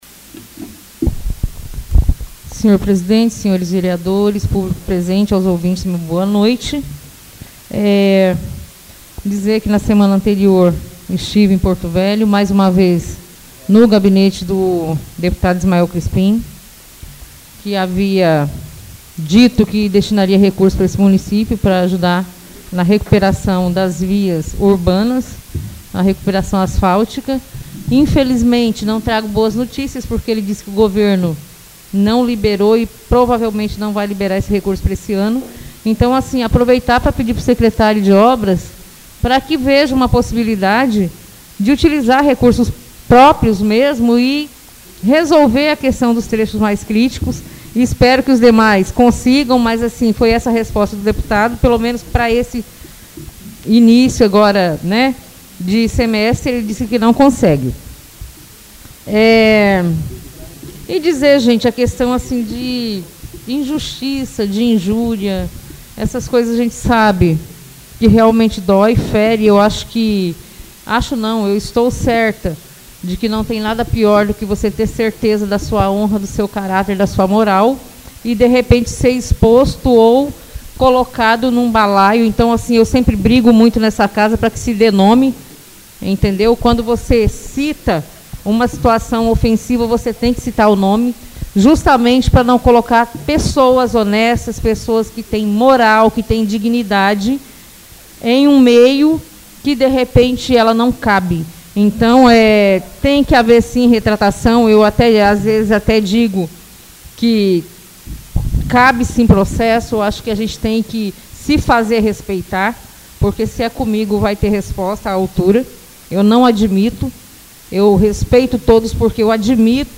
Oradores das Explicações Pessoais (21ª Ordinária da 3ª Sessão Legislativa da 6ª Legislatura)